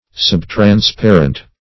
Subtransparent \Sub`trans*pa"rent\, a. Not perfectly transparent.